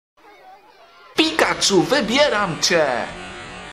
Play, download and share Pikachu Wybieram cie original sound button!!!!
pikachu-wybieram-cie-parodia-pokemon-go-2.mp3